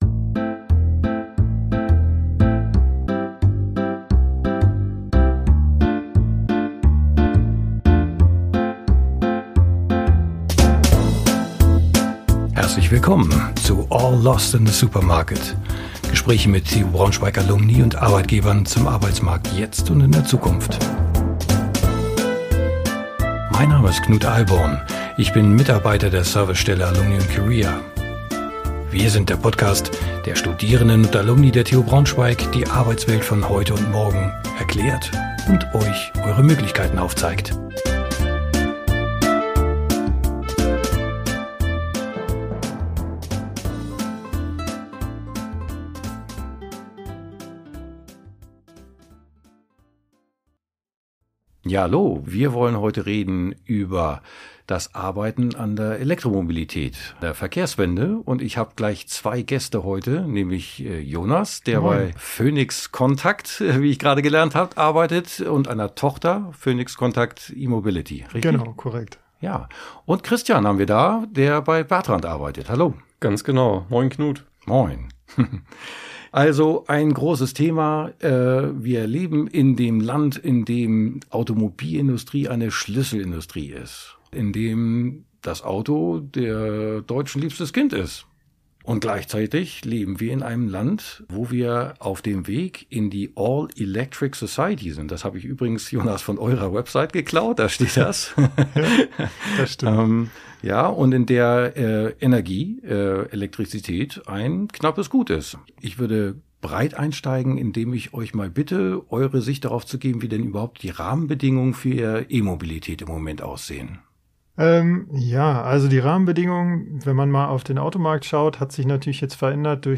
Arbeiten an Elektromobilität (mit Phoenix Contact E-Mobility und Bertrandt) ~ All lost in the supermarket – Gespräche mit Alumni der TU Braunschweig zum Arbeitsmarkt jetzt und in der Zukunft Podcast